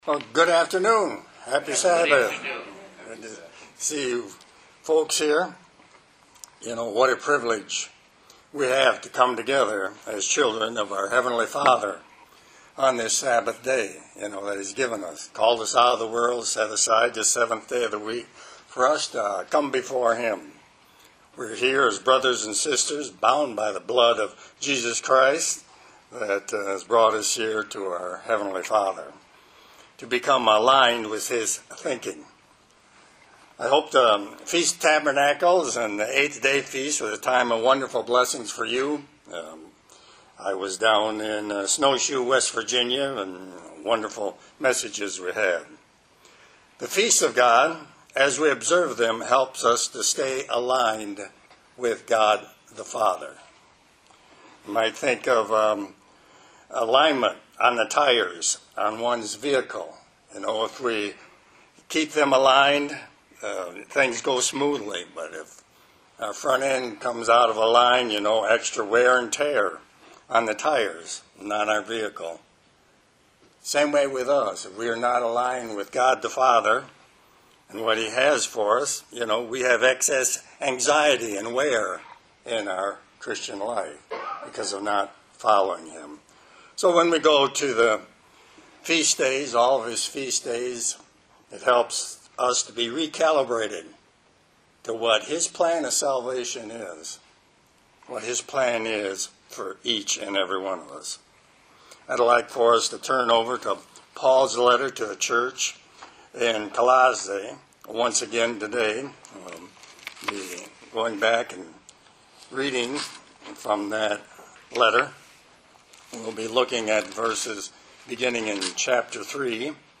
Sermon
Given in Detroit, MI